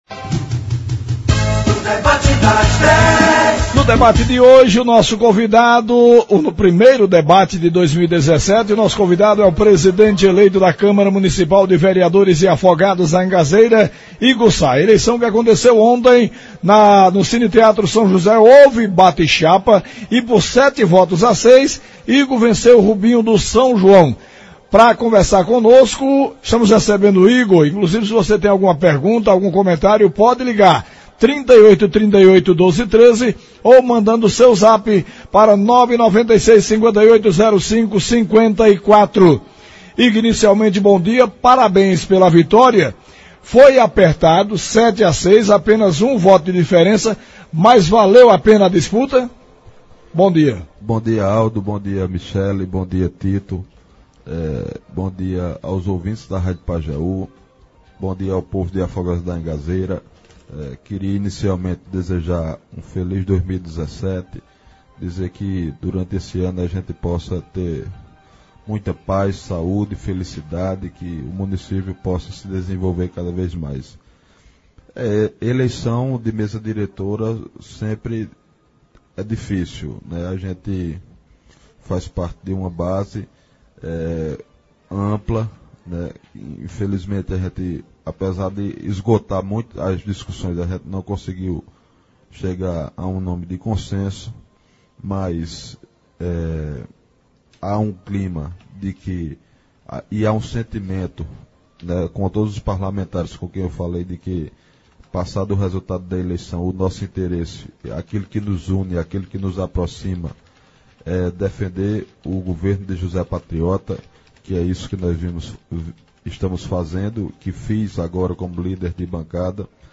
Afogados: em entrevista Igor Mariano diz que pretende desenvolver um trabalho diferenciado a frente do poder legislativo